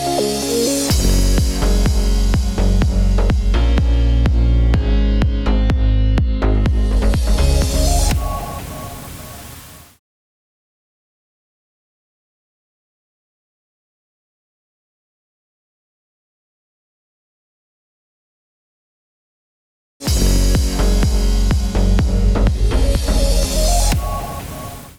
Bumper: 10 sekunder